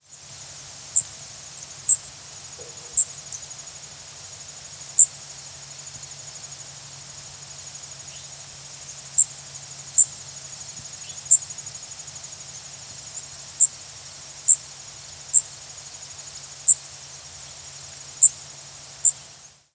Blackpoll Warbler diurnal flight calls
Bird in flight with Eastern Towhee and Yellow-rumped warbler calling in the background.